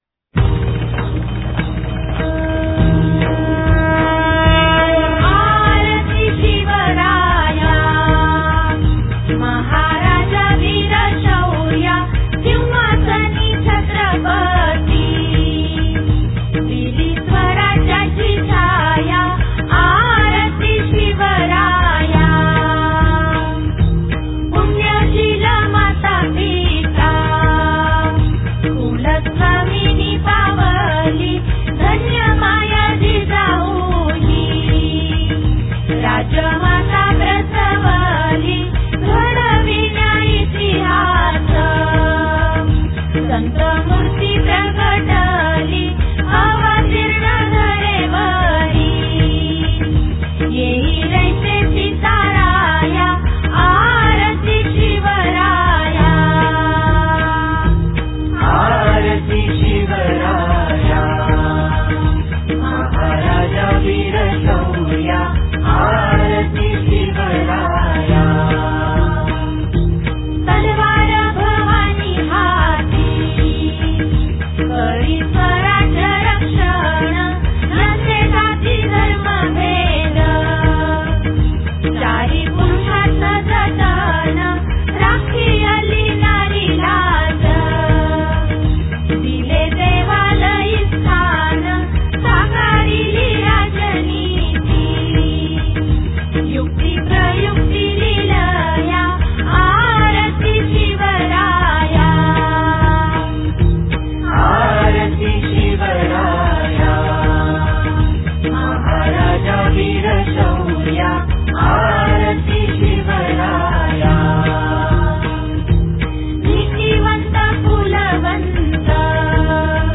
सुप्रसिद्ध कीर्तनकार यांनी गायन केलेली आहे.